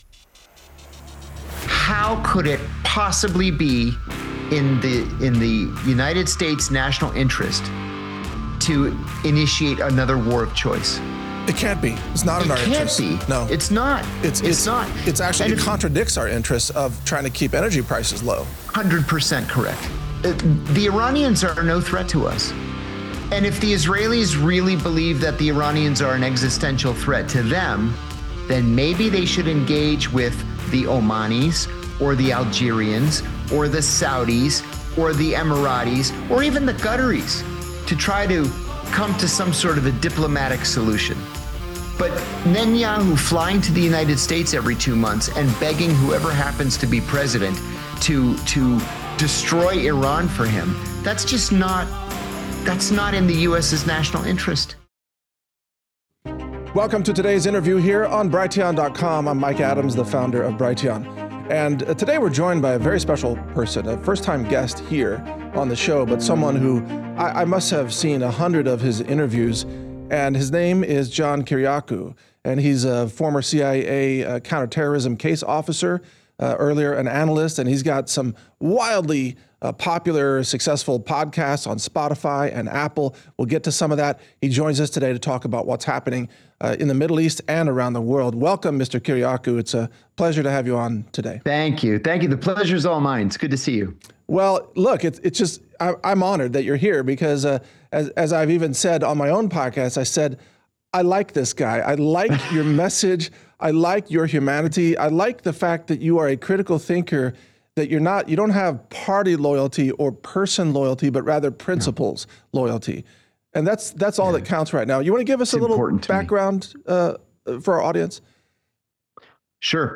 John Kiriakou Interview on Netanyahu's Attempt to Force Trump into Deadly War with Iran - Natural News Radio